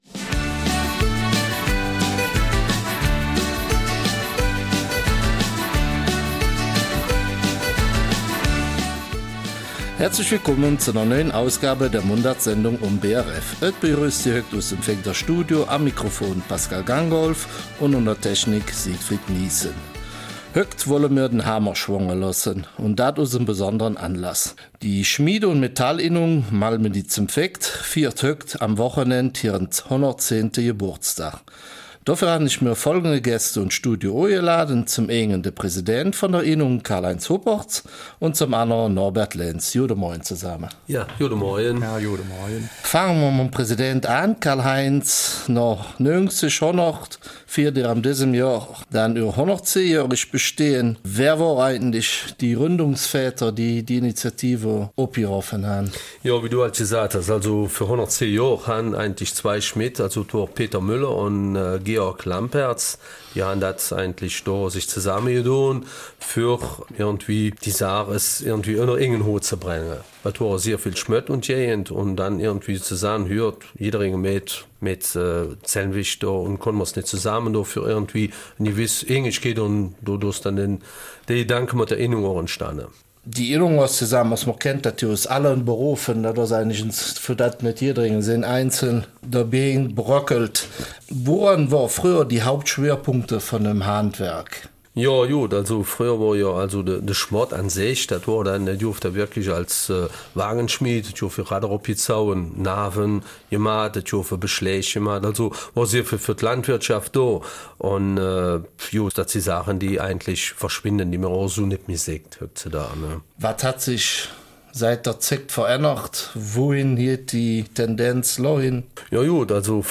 Eifeler Mundart: 110 Jahre Schmiede- und Metallinnung Malmedy-St. Vith